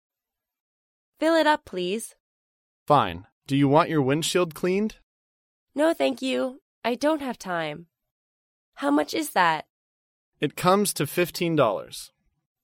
在线英语听力室高频英语口语对话 第496期:加高级汽油的听力文件下载,《高频英语口语对话》栏目包含了日常生活中经常使用的英语情景对话，是学习英语口语，能够帮助英语爱好者在听英语对话的过程中，积累英语口语习语知识，提高英语听说水平，并通过栏目中的中英文字幕和音频MP3文件，提高英语语感。